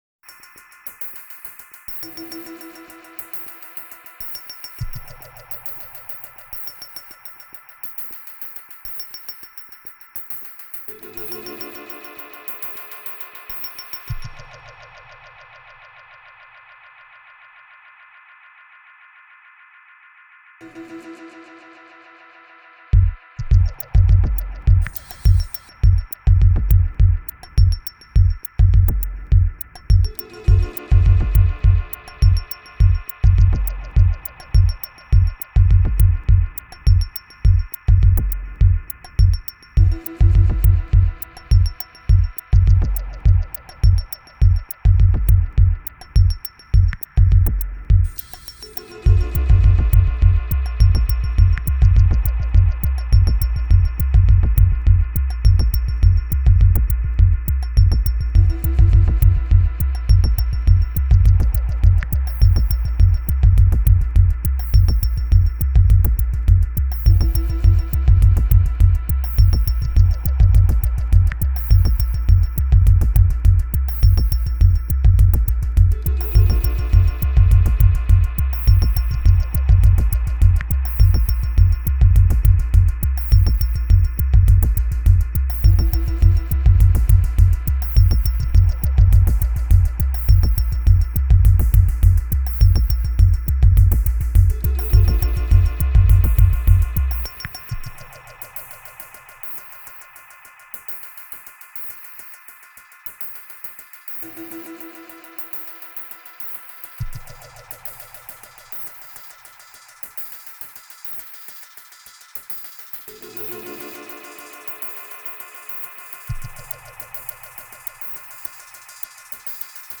True, the sound here is definitely unique.
electronic, techno, minimal, drone, downtempo